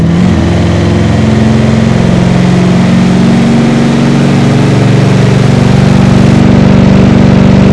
f350_gear.wav